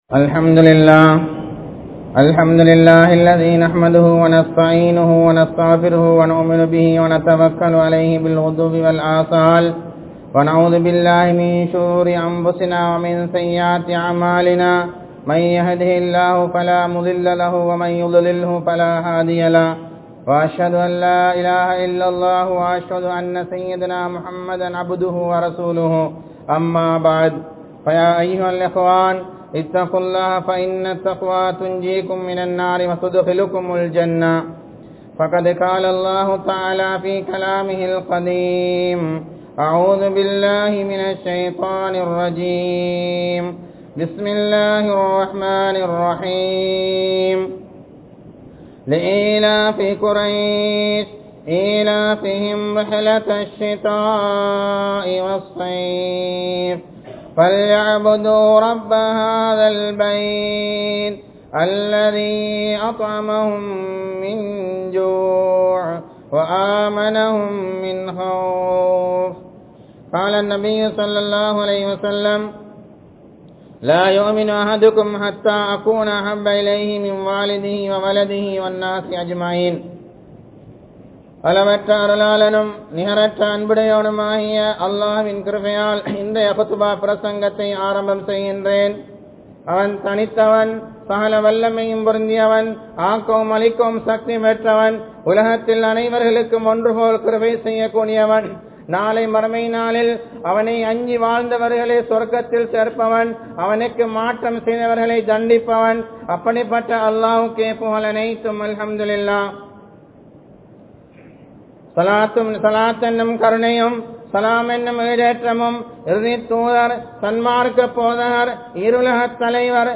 Nabi(SAW)Avarhalin Sirappu (நபி(ஸல்)அவர்களின் சிறப்பு) | Audio Bayans | All Ceylon Muslim Youth Community | Addalaichenai
Masjidun Noor Jumua Masjidh